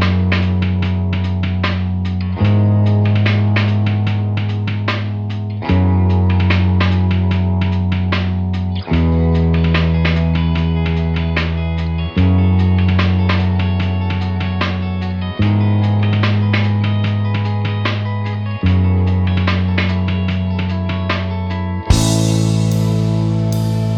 No Lead Guitar Rock 3:36 Buy £1.50